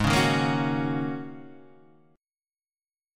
Ab9sus4 Chord